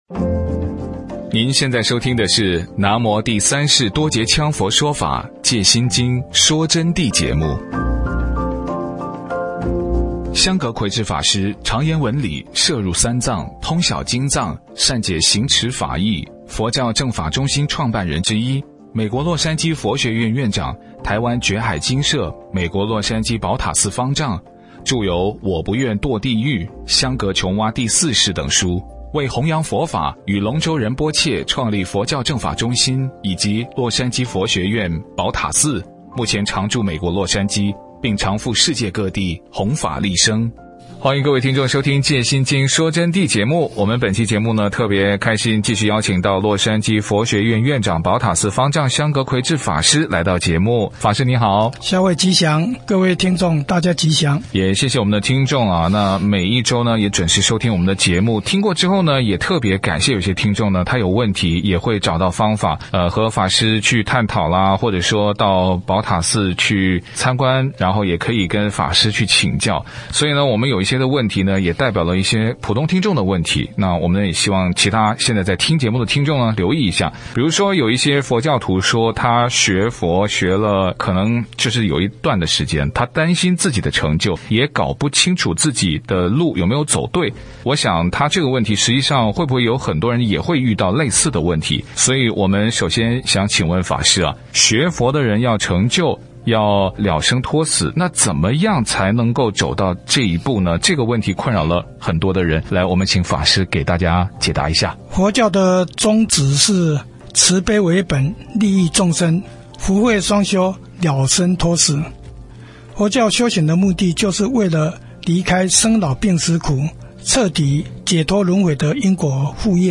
佛弟子访谈（二十九）学佛修行解脱轮回必经的要道是什么？如何找到真正的佛菩萨应世之师？